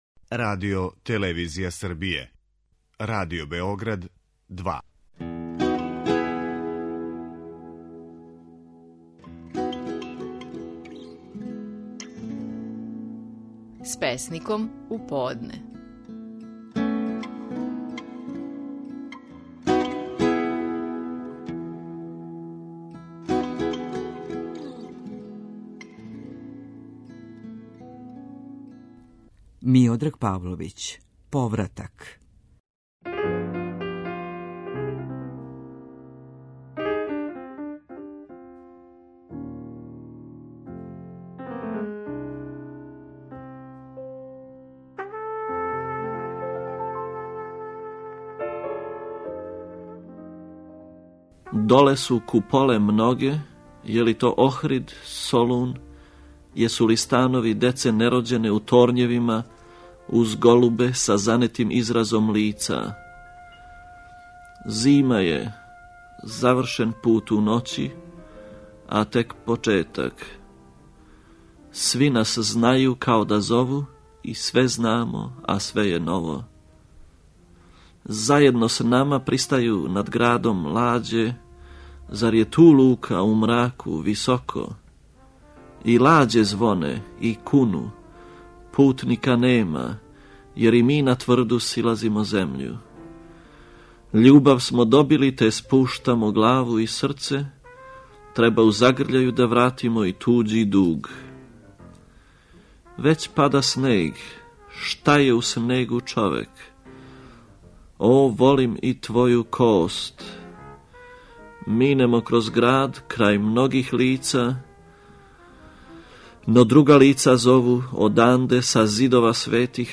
Стихови наших најпознатијих песника, у интерпретацији аутора.
Миодраг Павловић говори своју песму: „Повратак".